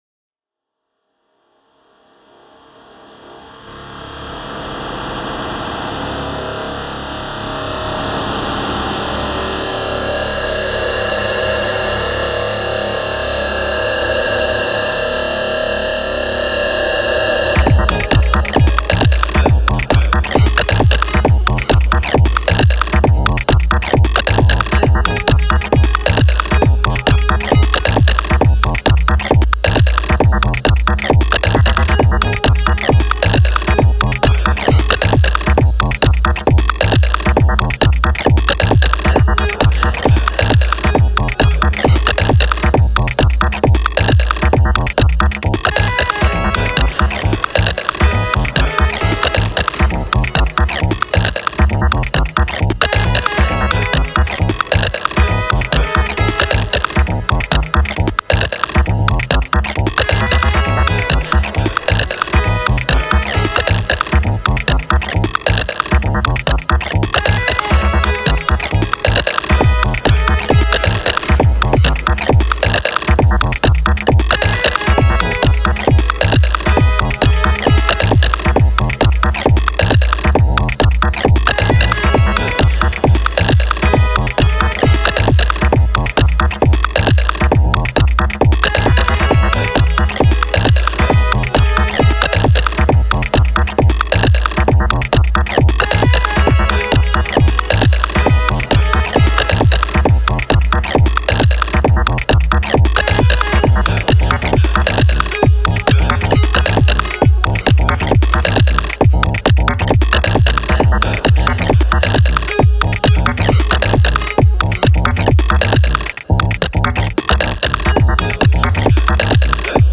Here's a mix of all of the submissions, ordered by upload date.